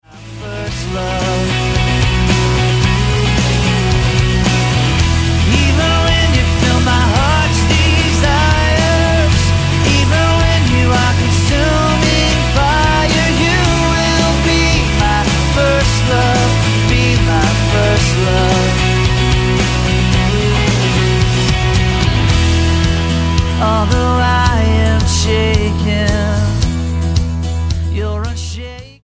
Rock Album
Style: Rock